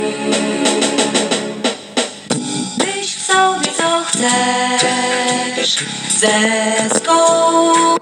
Kilkusekundowe nagranie z kasety
Witam, Na kasecie (późne lata 70-te, 80-te lub 90-te) znalazłem taki oto krótki kawałek piosenki. Wydaje mi się, że jest to początek utworu.